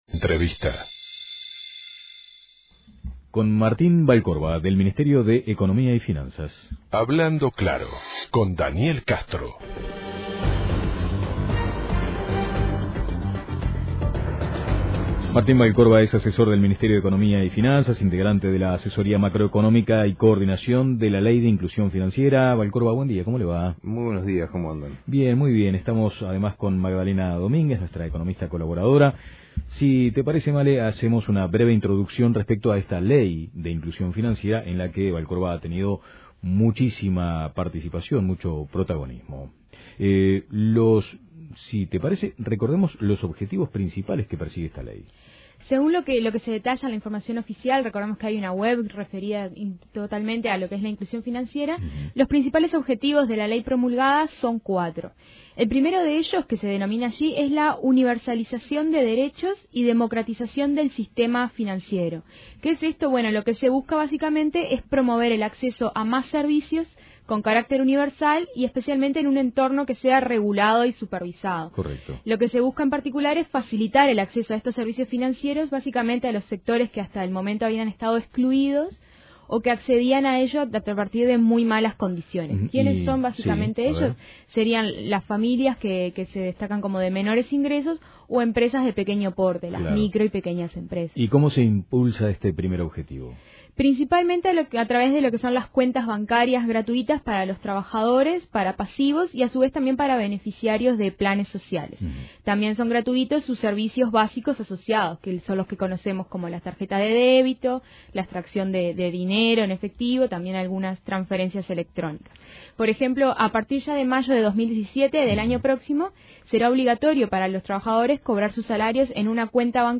Escuche la entrevista completa aquí: Descargar Audio no soportado